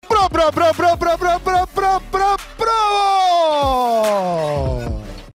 bravo-engine-starting.mp3